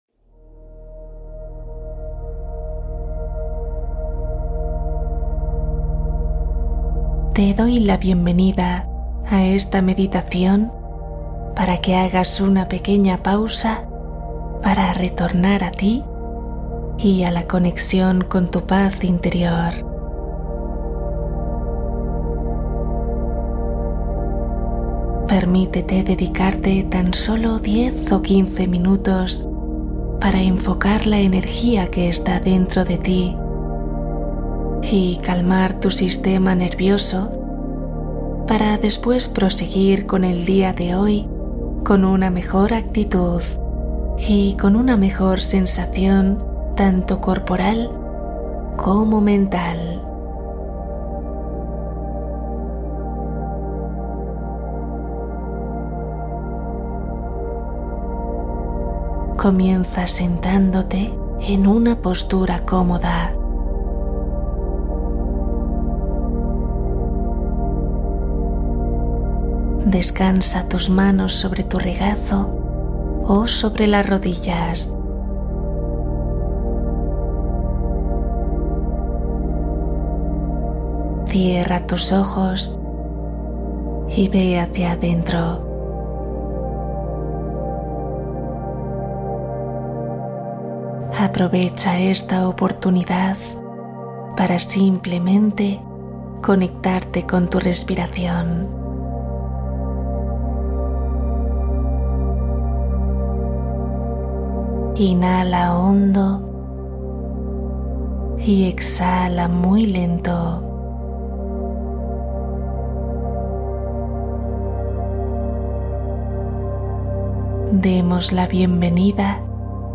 Viaje astral Meditación guiada para dormir profundo